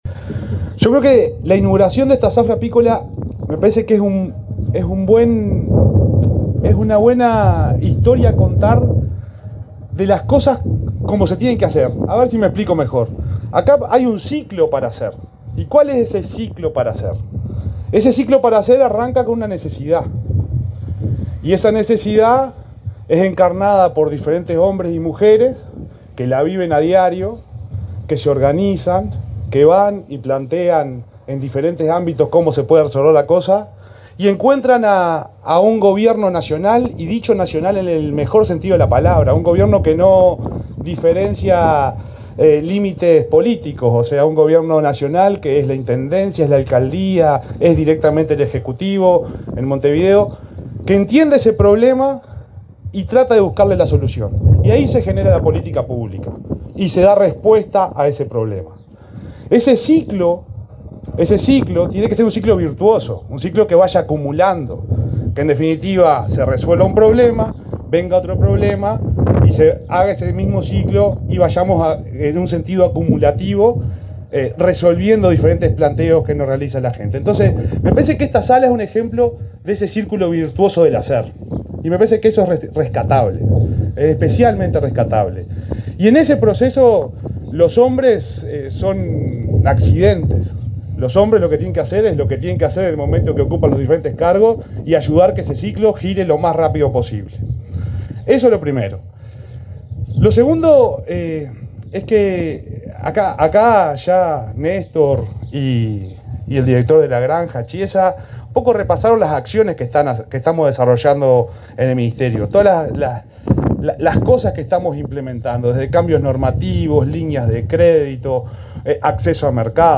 Palabras de autoridades en lanzamiento de zafra apícola
El subsecretario de Ganadería, Ignacio Buffa, y el director nacional de la Granja, Nicolás Chiesa, participaron este viernes 4 en la inauguración de